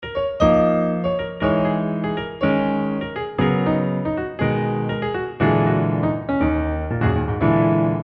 黑色象牙色钢琴
描述：降E小调阿诺
Tag: 120 bpm Jazz Loops Piano Loops 1.35 MB wav Key : Unknown